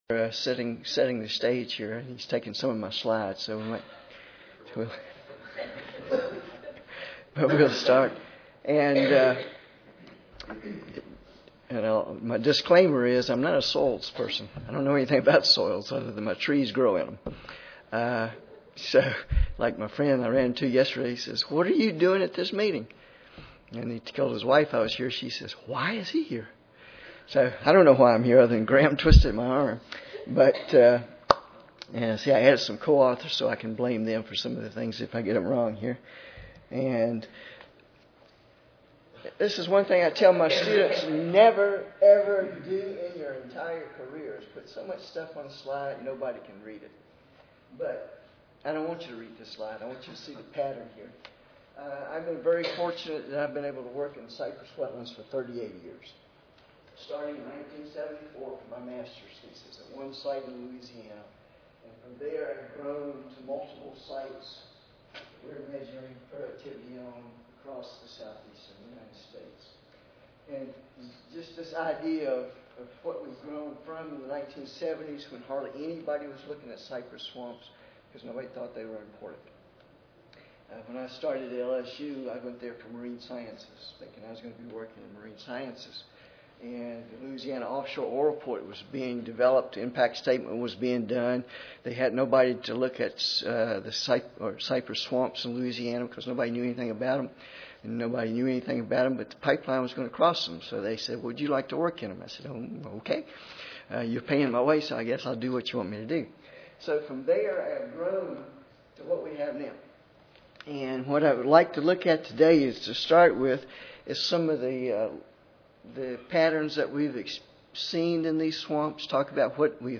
Auburn University Recorded Presentation Audio File